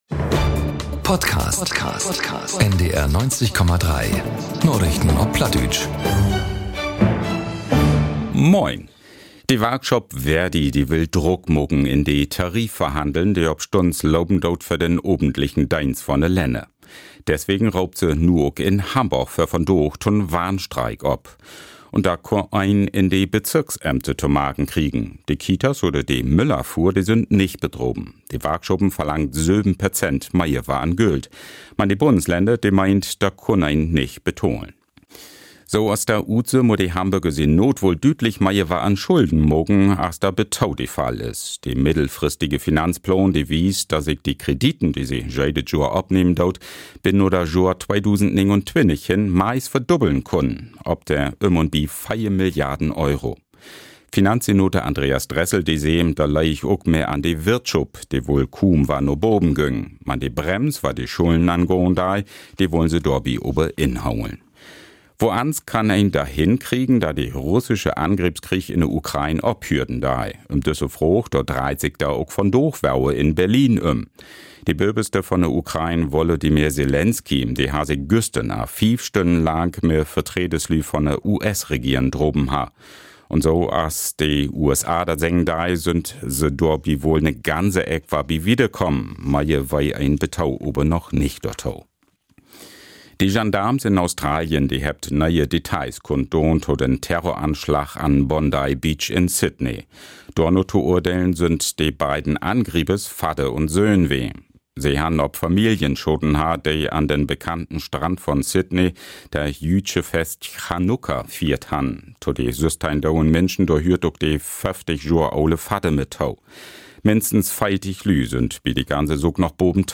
aktuellen Nachrichten auf Plattdeutsch.